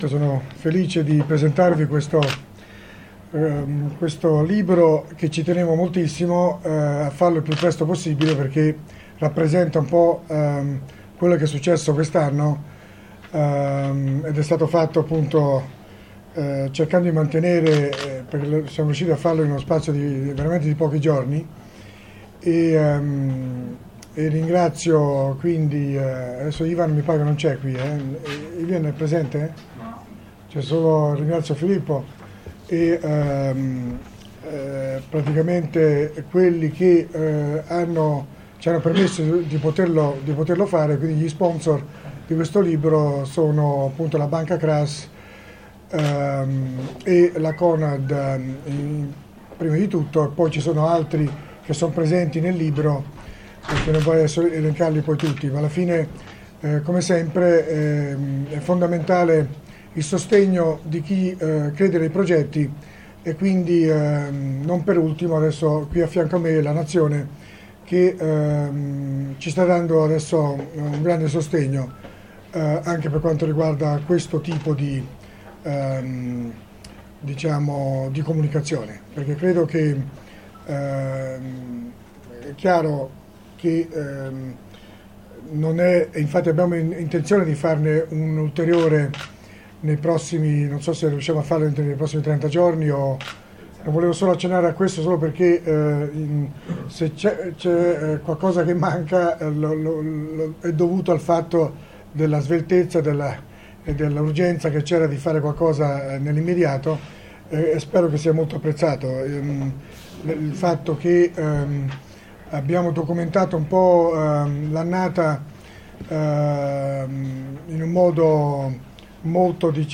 in conferenza stampa